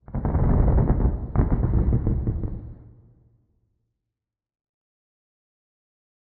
Minecraft Version Minecraft Version latest Latest Release | Latest Snapshot latest / assets / minecraft / sounds / ambient / nether / warped_forest / creak4.ogg Compare With Compare With Latest Release | Latest Snapshot
creak4.ogg